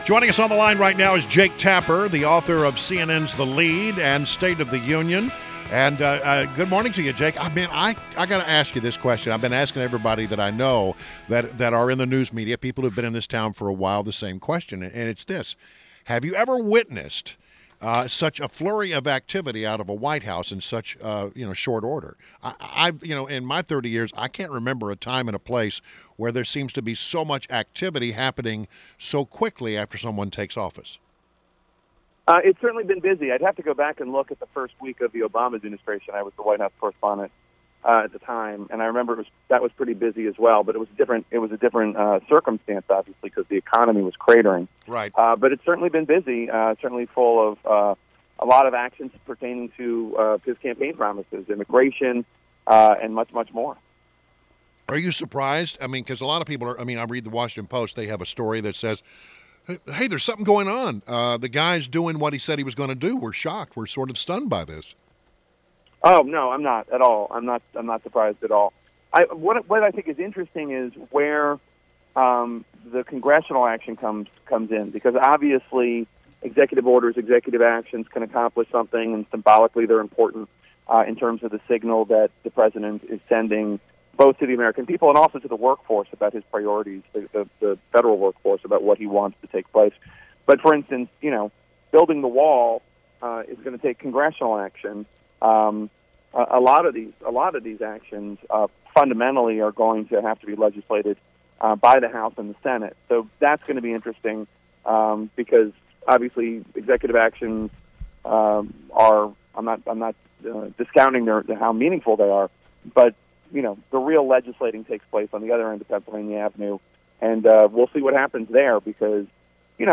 WMAL Interview - JAKE TAPPER - 01.26.17
INTERVIEW – JAKE TAPPER – Anchor of CNN’s THE LEAD AND STATE OF THE UNION